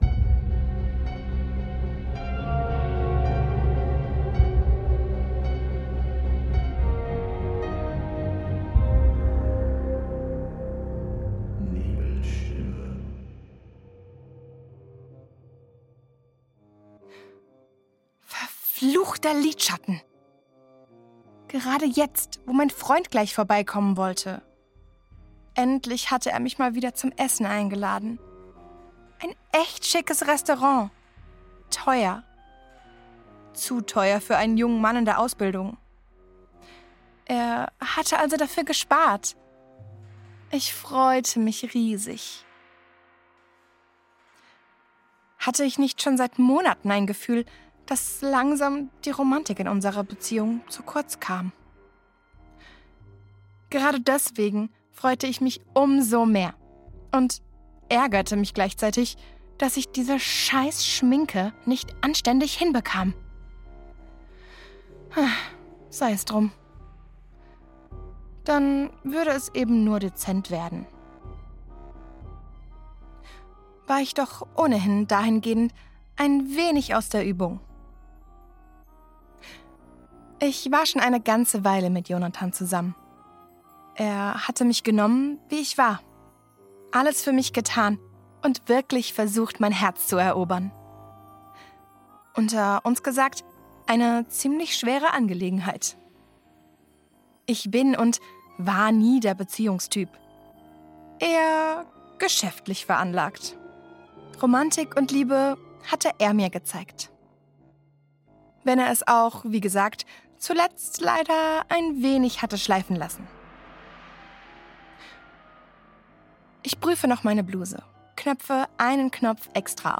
Du hörst hier die neu vertonte Version meiner früheren Aufnahme auf Youtube – mehr Tiefe im Sound, mehr Atmosphäre im Erzählen, mehr Dunkelheit in den Pausen.